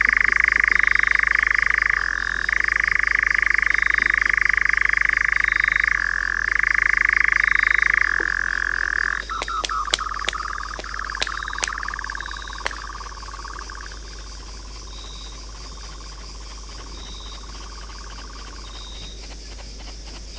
Caprimulgus europaeus - Nightjar - Succiacapre
- POSITION: Poderone near Magliano in Toscana, LAT.N 42°36'/LONG.E 11°17'- ALTITUDE: +130 m. - VOCALIZATION TYPE: typical territorial call - SEX/AGE: unknown. - COMMENT: This is the end of a long call bout. Note the wing clapping intermingled to the queue ending the call phrase. Background crickets.